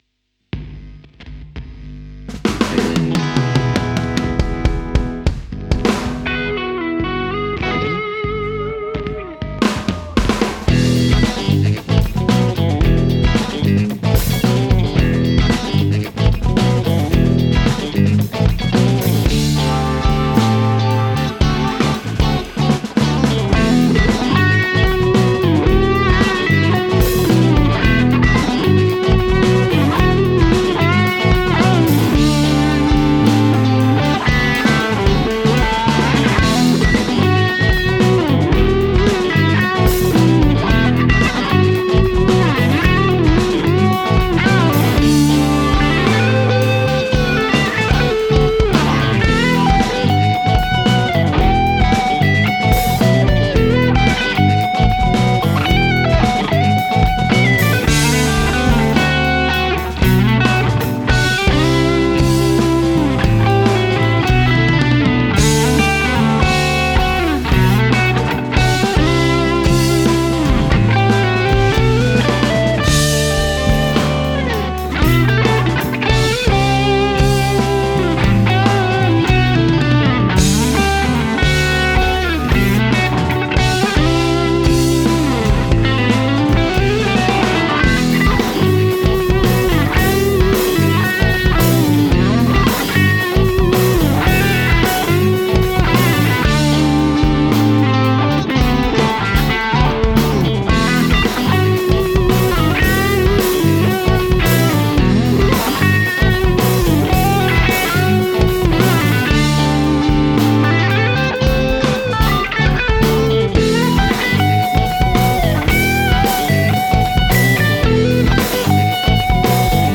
Started in 2002, this tune is based on a simple funky blues-rock riff in E, with a few rhythm tricks and odd measures here and there.
The other challenge was to find the right tone I had in mind for the melody.
• Electric Guitars: Fender partcaster (a.k.a. Miss Mojo) for the main riff and solo (with both pickups in series for the solo, thanks to a freeway switch), PRS Special semi-hollow for the melody, with the not yet released PolyVibe plug-in in reverse mode for a Wah type of sound.
• Bass Guitar: Warwick Streamer (P-Bass style pickup)
• Drums & Percussions: Roland V-Drums triggering the Addictive Drums plug-in, by XLN Audio.
• B3 Organ: Nord Stage 2.
• All effects plug-ins (guitar tones, reverbs, Univibe, wah, compressors etc.) by Blue Cat Audio.
Note: while the “mojo” noise at the beginning is definitely real analog buzz, the feedback at the end of the tune is generated artificially, thanks to the AcouFiend plug-in.